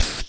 人类节拍器" hat5
描述：节拍盒帽子
声道立体声